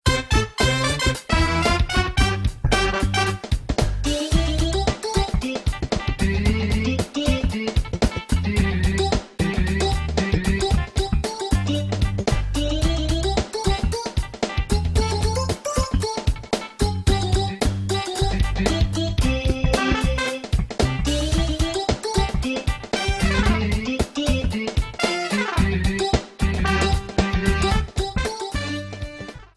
Fair use music sample
Added fade-out at the end